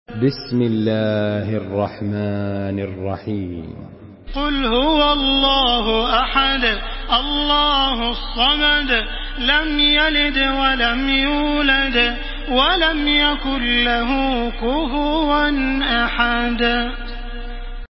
Surah Al-Ikhlas MP3 in the Voice of Makkah Taraweeh 1434 in Hafs Narration
Murattal Hafs An Asim